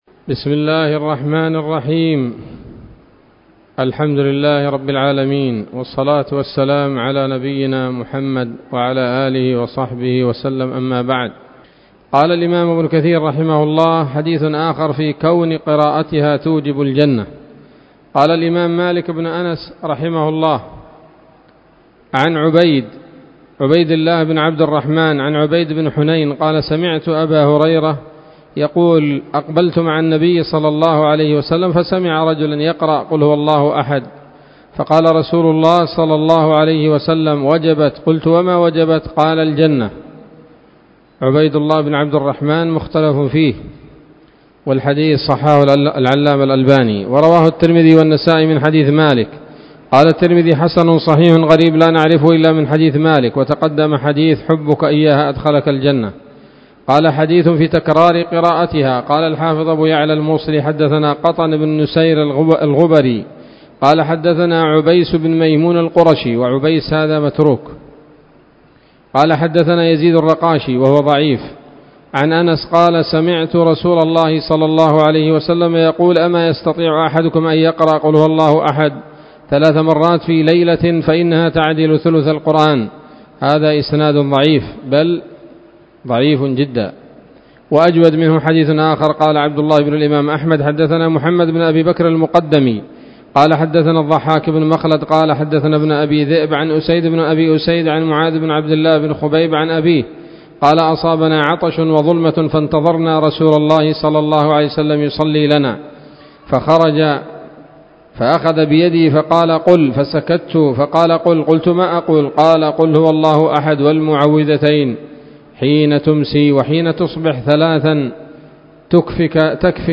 الدرس الثالث من سورة الإخلاص من تفسير ابن كثير رحمه الله تعالى